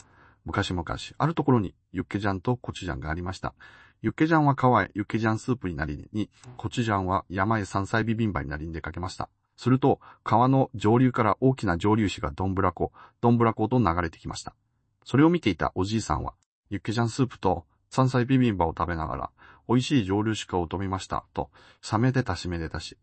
クローン音声
Vidnoz AIにはクローン音声という機能もあります。